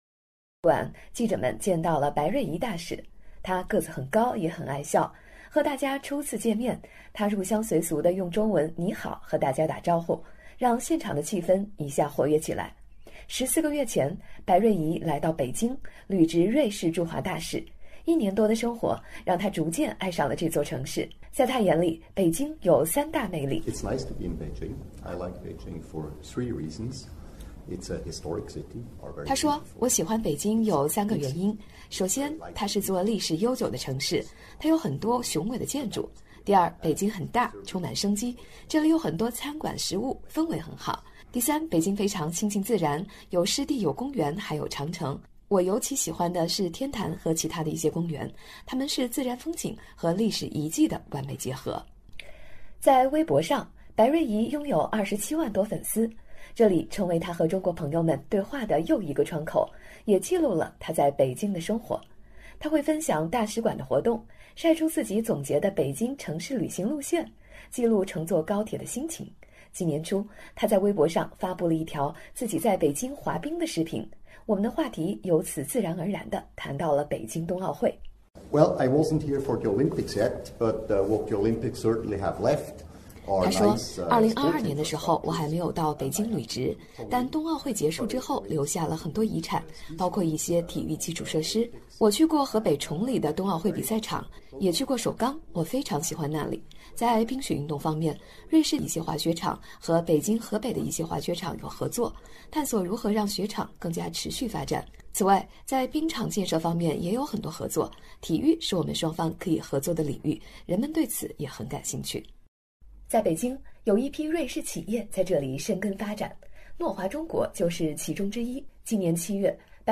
北京新闻广播：瑞士驻华大使白瑞谊：“候鸟往复迁徙，如同北京对世界张开怀抱”_采访报道 - 北京市人民政府外事办公室
在瑞士驻华大使馆，记者们见到了白瑞谊大使。